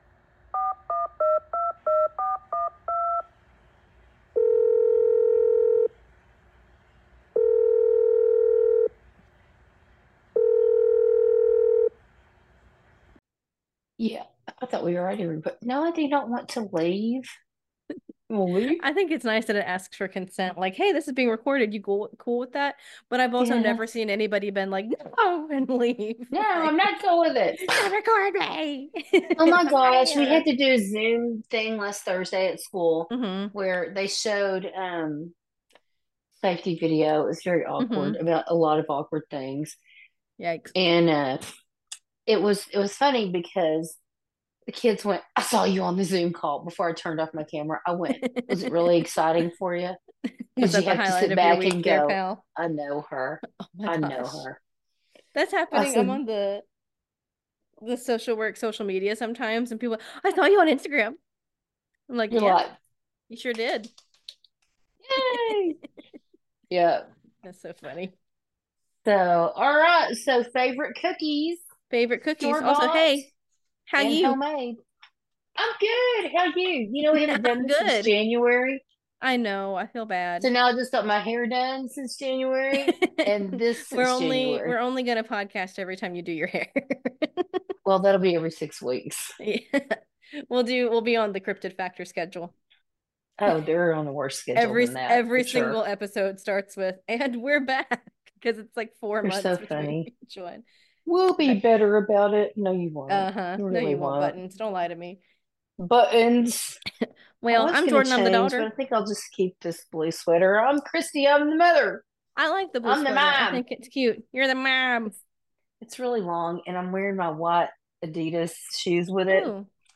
We explore our top 5 favorite cookies, both homemade and store bought. Is it really an episode of Call Your Mom if a dog doesn't bark?